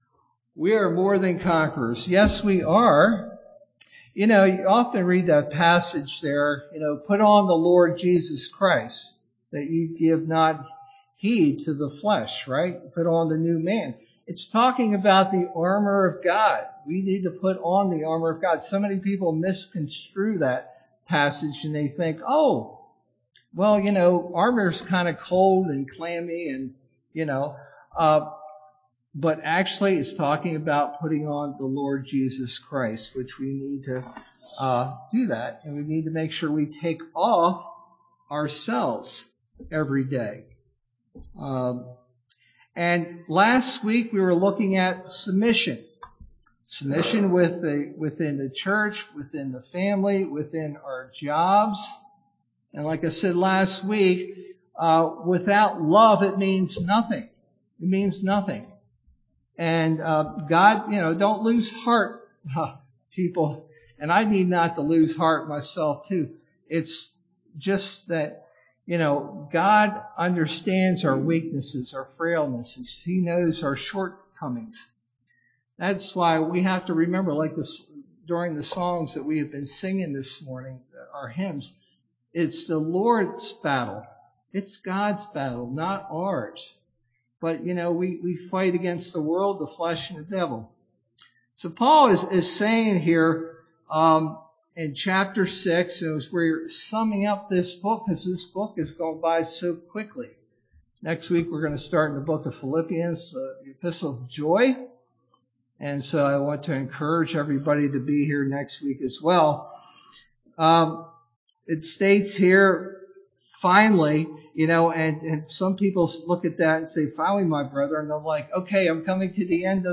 Sermon verse: Ephesians 6:10-24